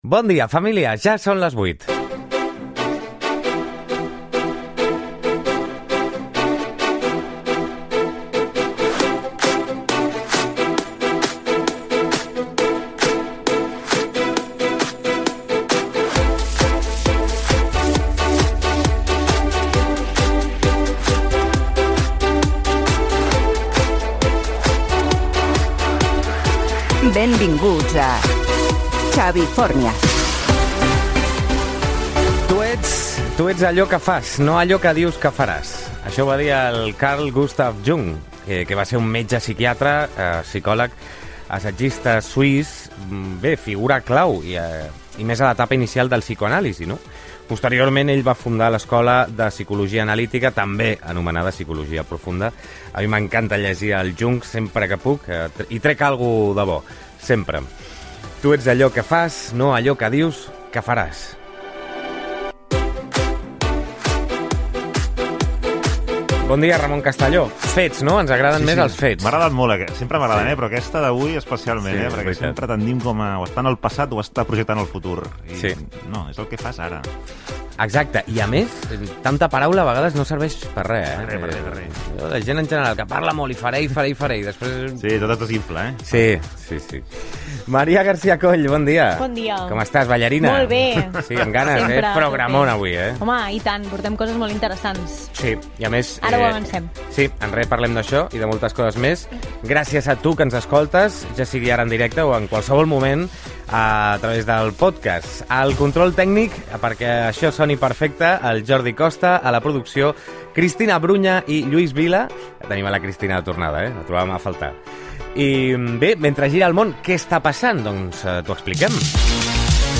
Hora, careta del programa, frase de Carl Gustav Jung, presentació de l'equip, informatiu: detingut l'home fugat que va atropellar vuit ciclistes a Castellbisbal
Info-entreteniment
FM